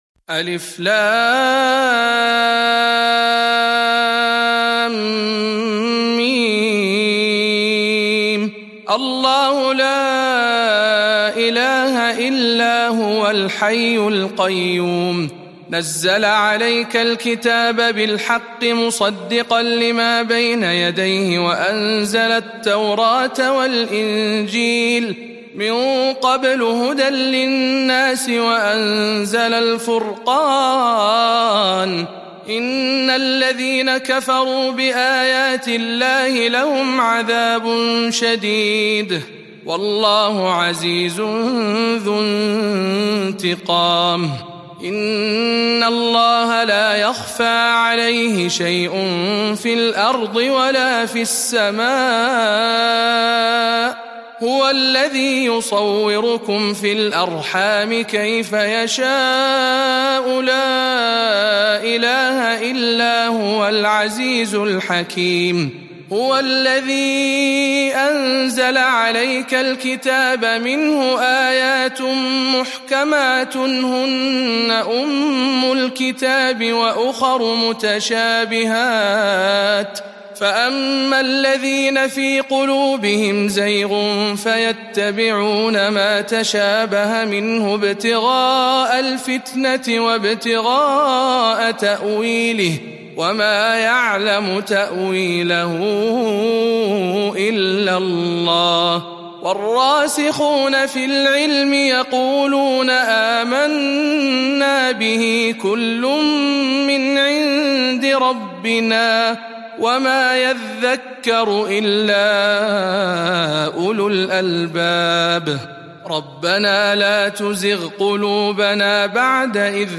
003. سورة آل عمران برواية شعبة عن عاصم - رمضان 1441 هـ